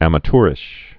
(ămə-tûrĭsh, -chr-, -tyr-)